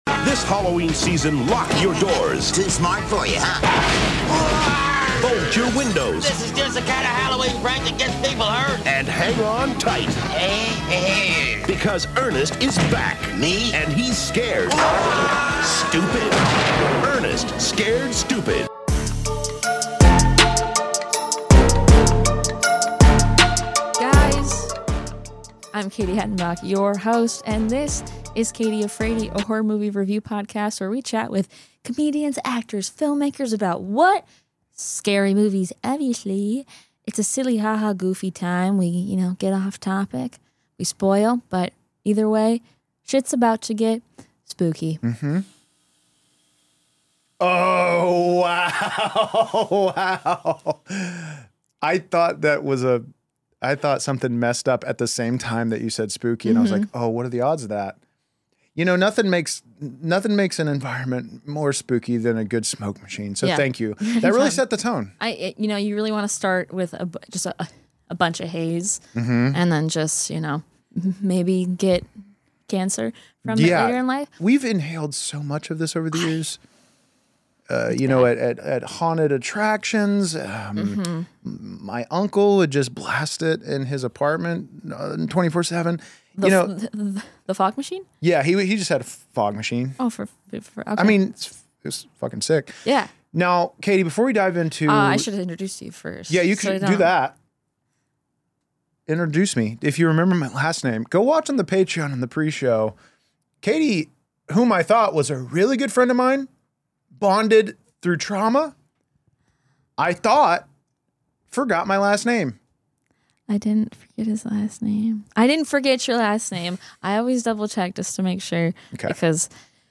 horror movie review podcast